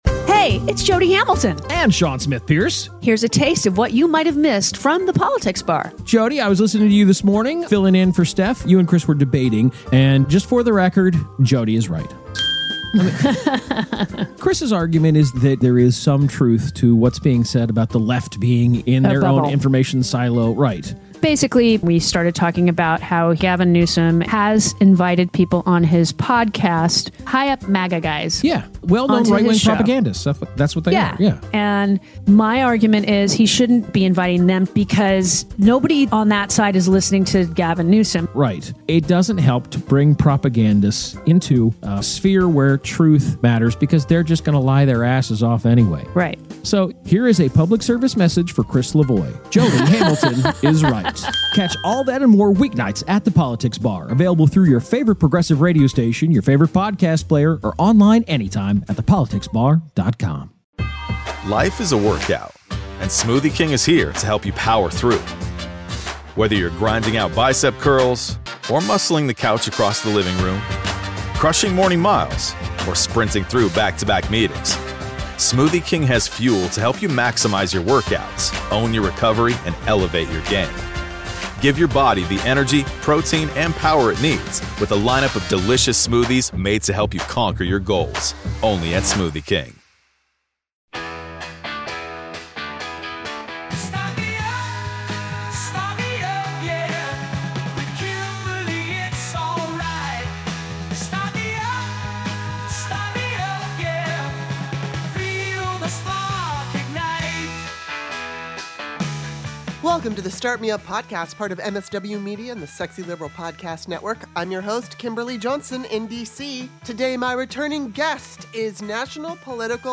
I'm not always politically correct and I'm a huge fan of loose talk and salty language. Sarcasm is guaranteed and political correctness will be at a minimum. This podcast is for anyone who wants to listen to compelling conversations with a variety of guests about current events and controversial topics.